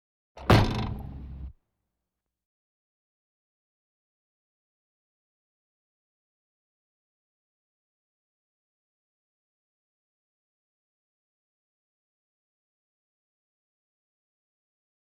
Truck Cab Door Latch Down Sound
transport
Truck Cab Door Latch Down